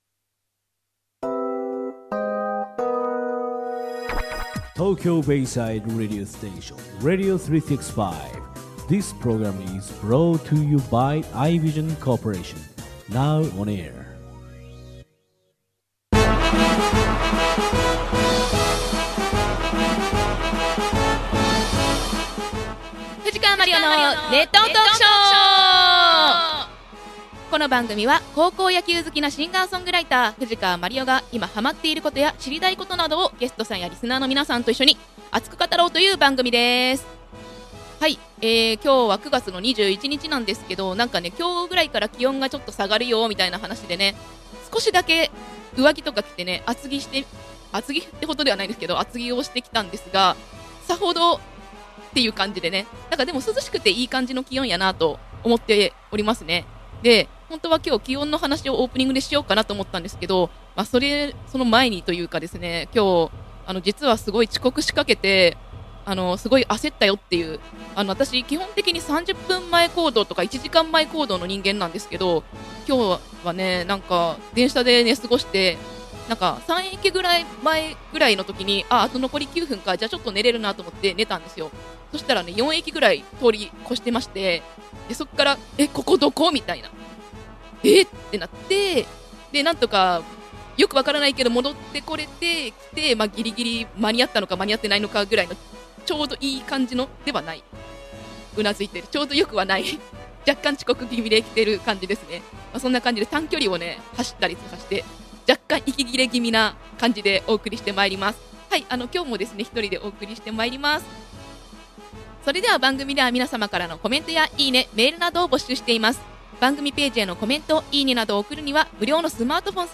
前回宣言通り今回も一人でしたが、珍しく野球じゃない回です。収録前にやらかしたので若干息切れ気味でお送りしております。